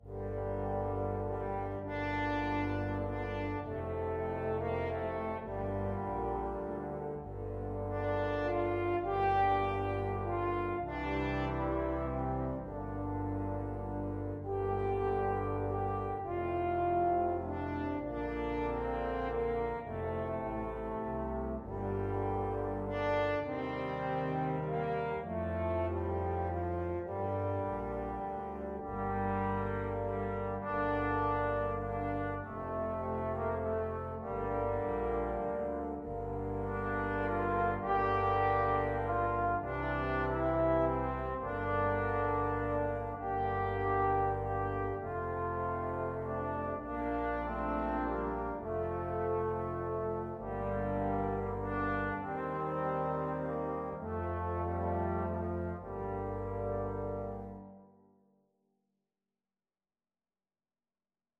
French Horn 1French Horn 2Trombone 1Trombone 2Tuba
3/4 (View more 3/4 Music)
G minor (Sounding Pitch) (View more G minor Music for Brass Ensemble )
Lento
Brass Ensemble  (View more Easy Brass Ensemble Music)
Traditional (View more Traditional Brass Ensemble Music)